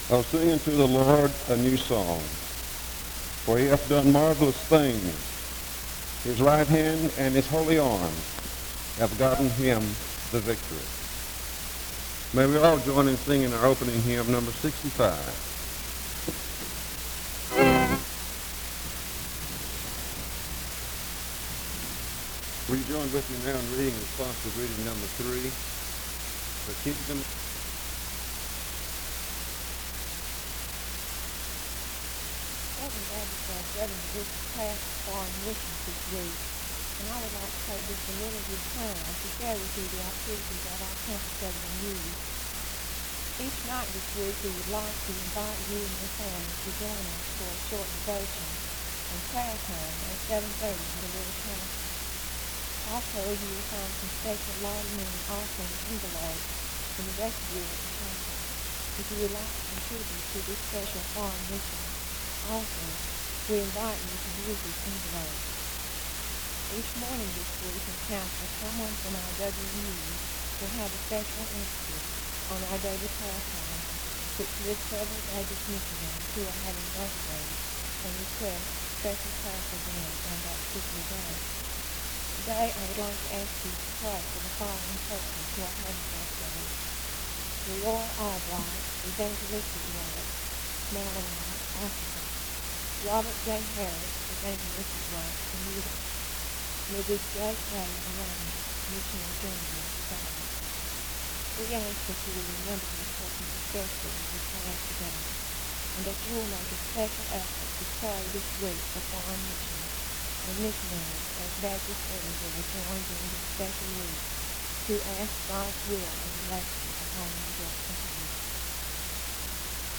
The service starts with an opening scripture reading from 0:00-0:15. An announcement concerning the Foreign Mission Board (currently known as the International Mission Board) is given from 0:30-2:00. A prayer is offered from 2:08-3:30. An introduction to the speaker is given from 3:35-6:06.
SEBTS Chapel and Special Event Recordings SEBTS Chapel and Special Event Recordings